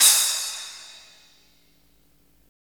Index of /90_sSampleCDs/Roland L-CD701/CYM_FX Cymbals 1/CYM_Splash menu
CYM SPLAS01R.wav